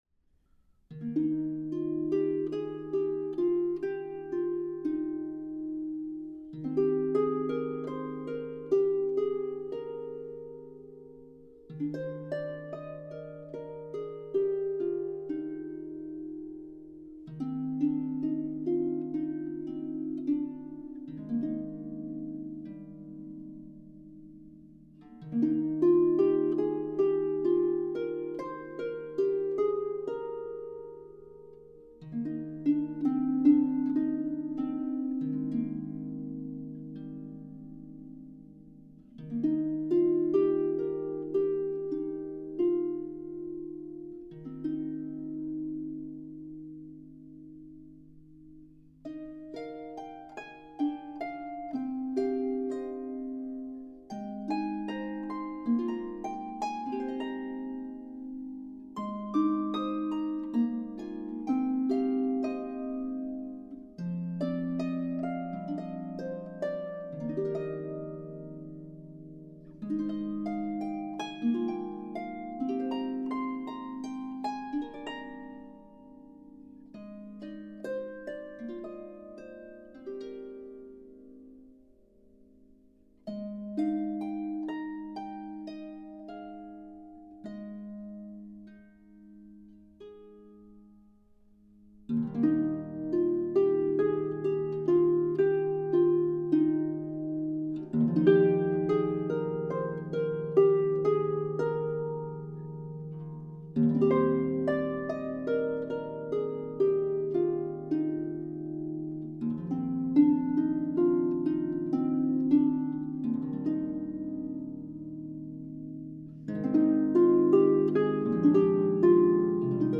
traditional Christmas carol